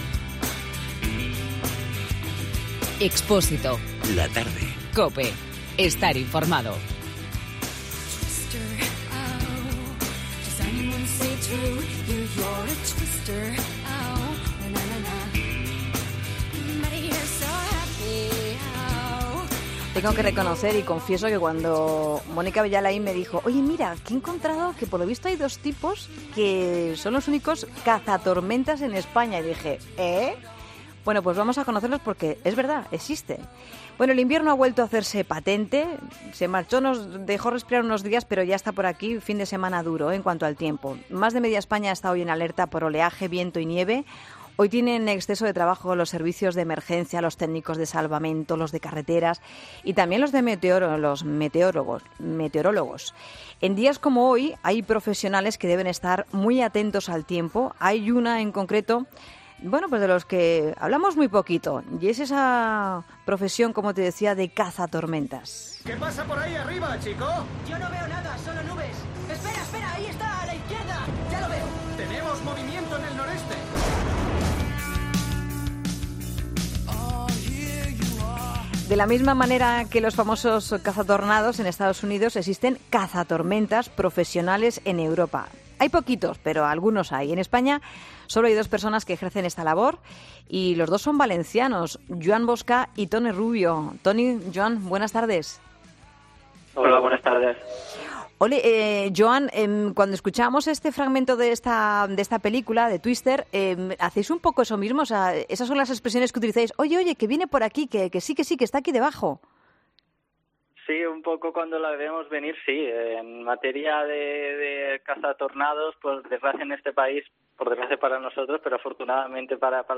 España cuenta con solo dos cazadores de tormentas profesionales. Hoy en 'La Tarde' hemos hablado con los dos sobre su peculiar oficio.